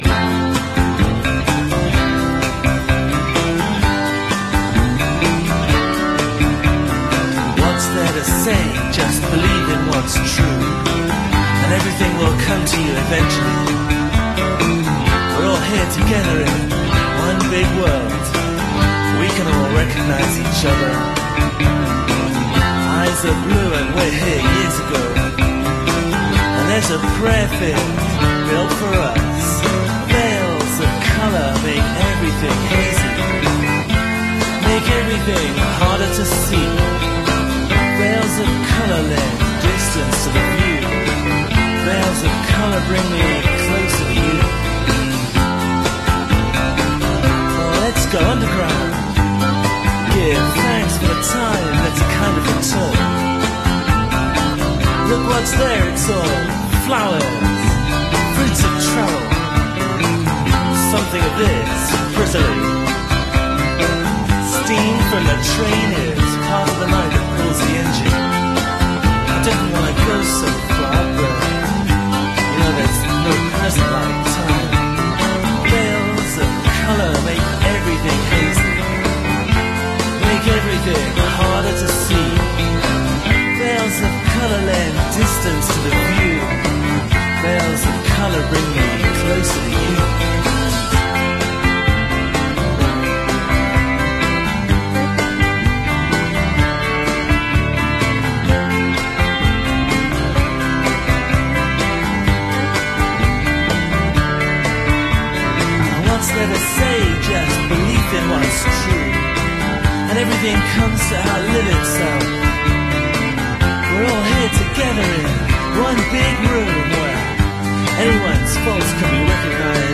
largely spoken poetic lyrics
a heavily guitar-centric band